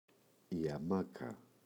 αμάκα, η [aꞋmaka]